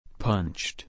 /pʌntʃ/